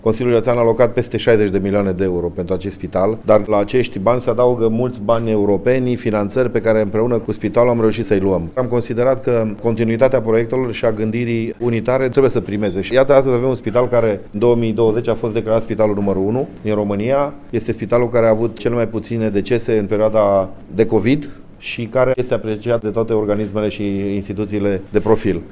Investitiile facute si fondurile europene atrase au salvat unitatea medicala care acum se afla printre spitalele de top din Romania. Presedintele Consiliului Judetean Cluj, Alin Tise, precizeaza ca numai pentru amenajarea Compartimentului de Primiri Urgente s-au investit 4,5 milioane de lei: